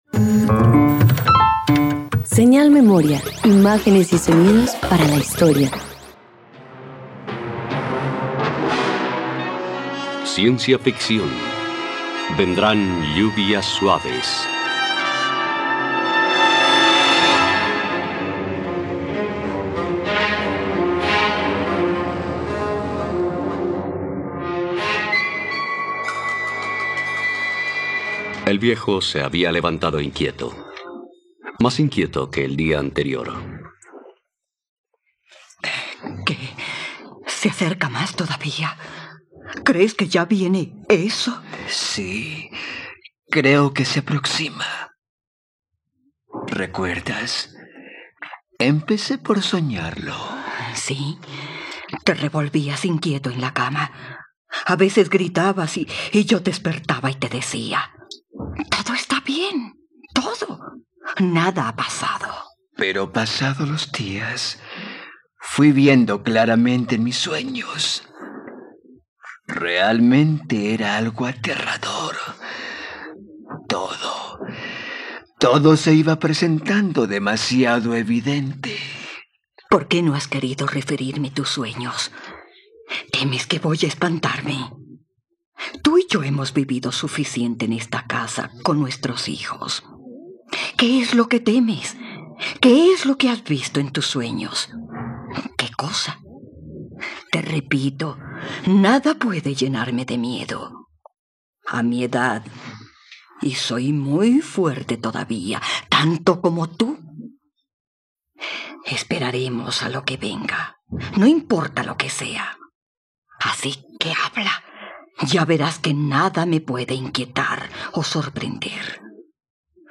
..Radioteatro. Disfruta la adaptación radiofónica de “Vendrán lluvias suaves”, una obra de Ray Bradbury.